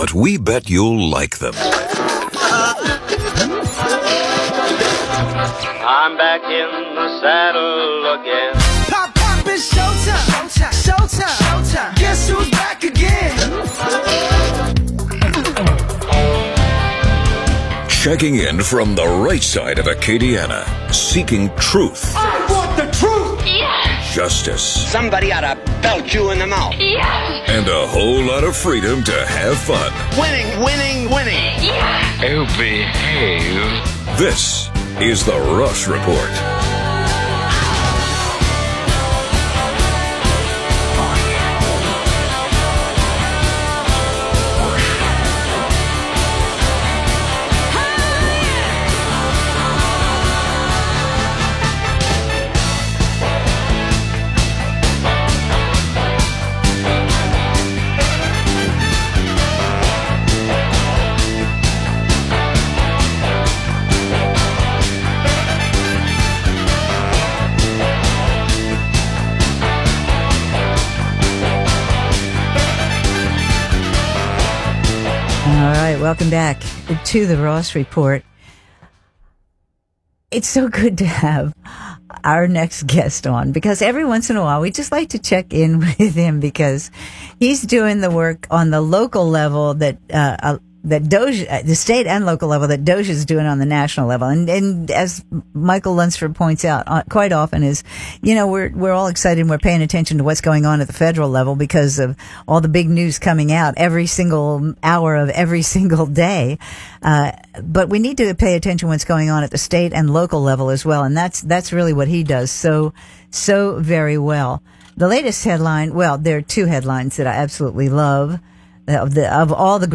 The Ross Report is fact-based commentary digging deep into the local, state and national news seeking truth, justice and freedom to have fun.